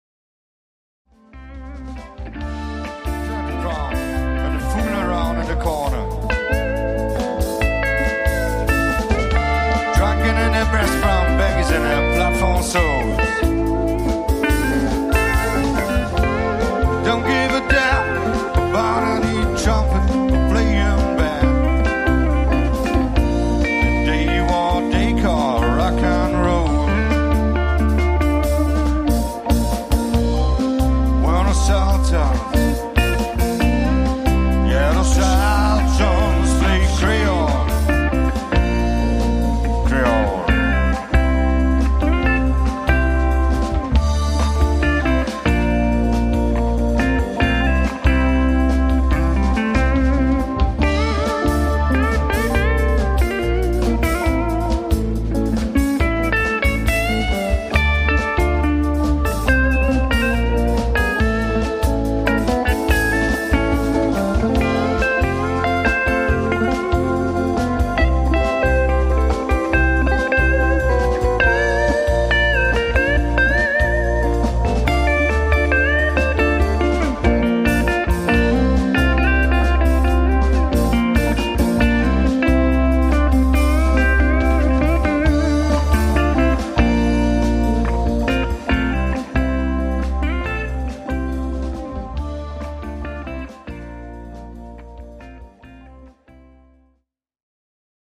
Live Mitschnitte von einem unserer Auftritte.